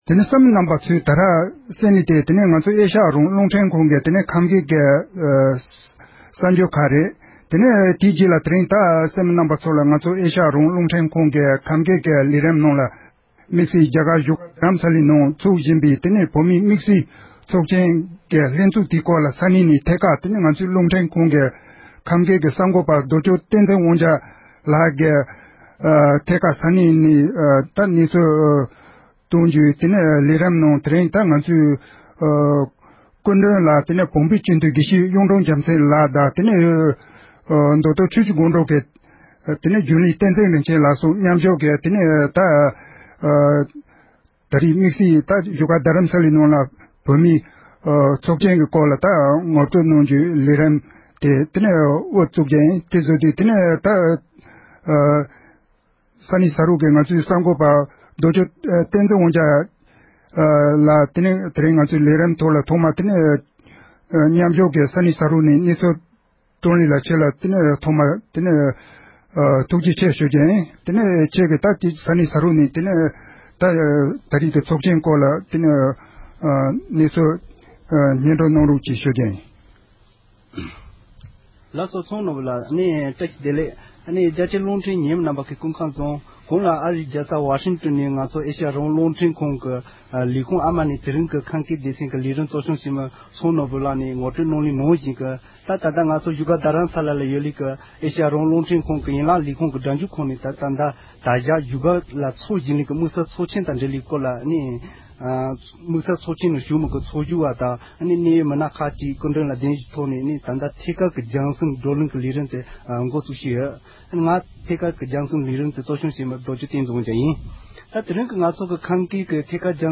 བཞུཌ་སྒར་དུ་ཚོཌ་བཞིན་པའི་དམིཌ་བསལ་ཚོཌ་ཆེན་ཉི་མ་བཞི་པའི་སྐོར་ཚོཌ་བཅར་བ་ཁག་དང་ལྷན་དུ་འདི་གའི་གསར་འགོད་པས་གླེང་མོལ་ཞུས་པ།